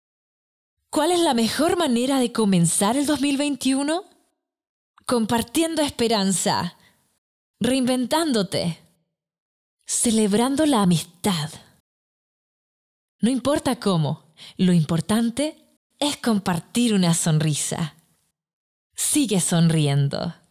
Bilingual Voiceover Artist and Singer-songwriter
Colgate COMMERCIAL CHILEAN SPANISH